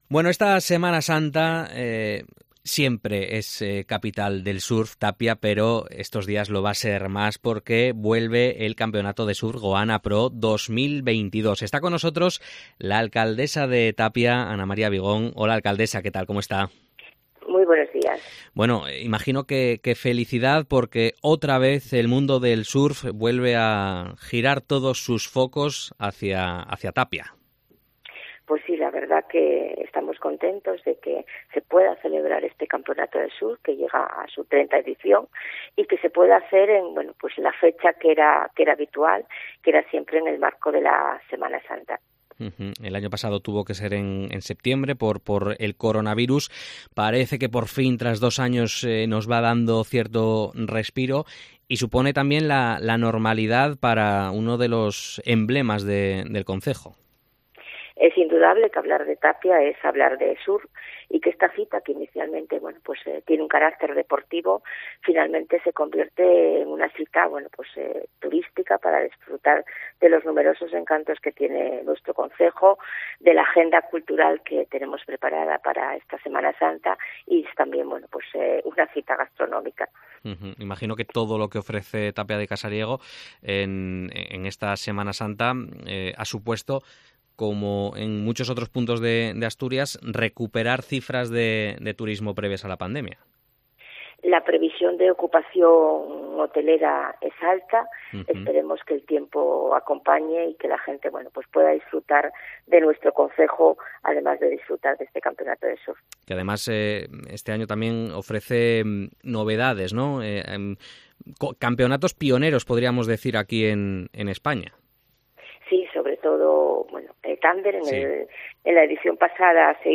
Entrevista a Ana María Vigón, alcaldesa de Tapia, sobre el campeonato de surf Goanna Pro 2022